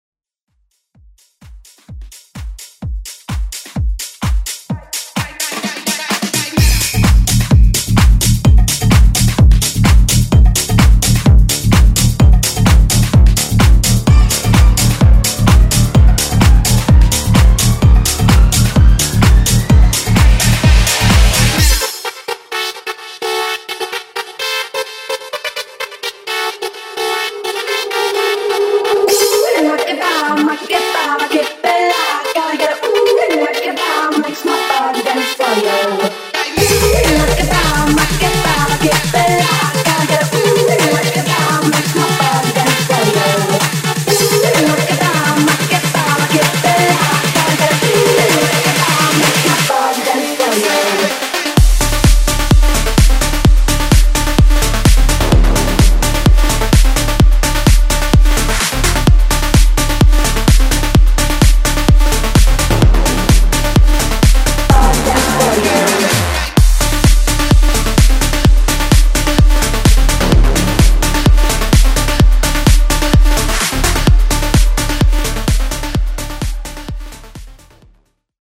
Genres: HIPHOP , R & B
Clean BPM: 105 Time